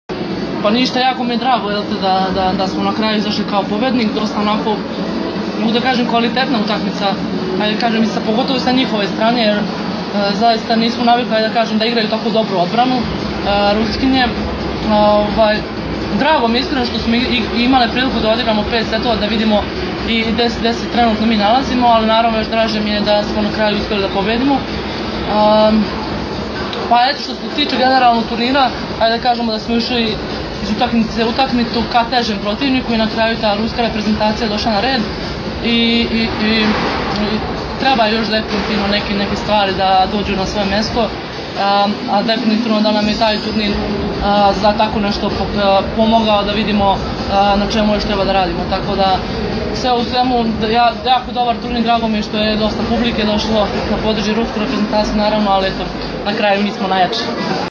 IZJAVA BRANKICE MIHAJLOVIĆ